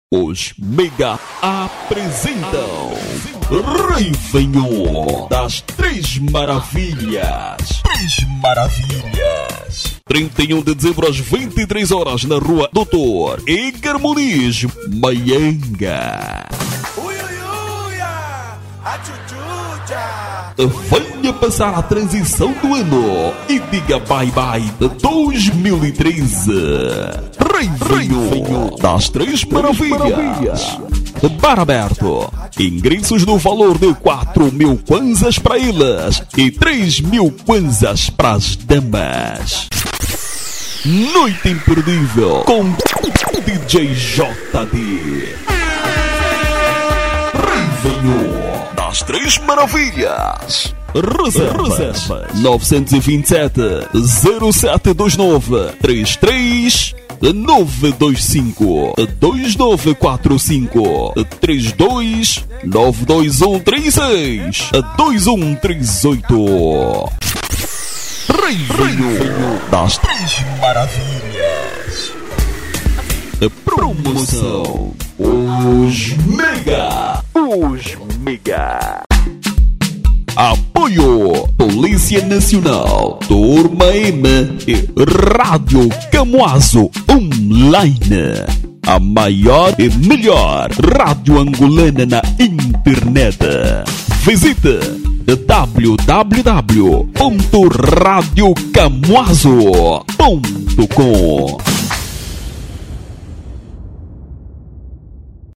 Masculino
Festa de Reveillon